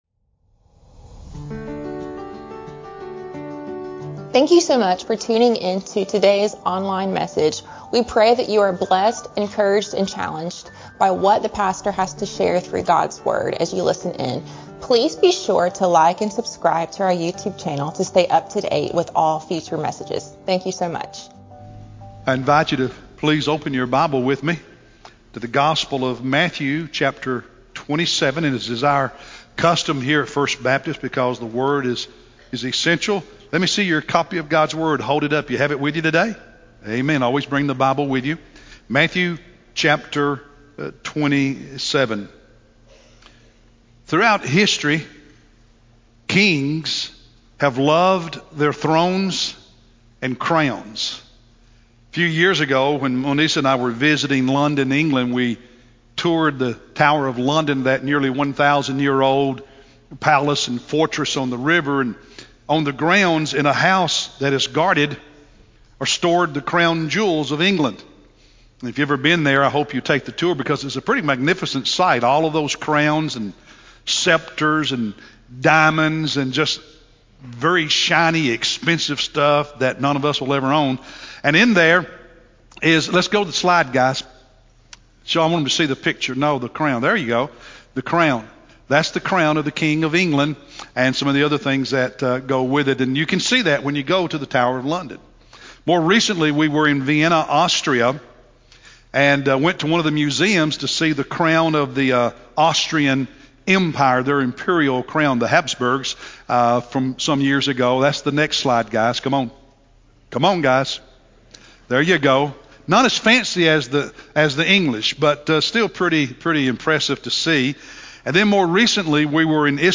Easter Sunday Sermon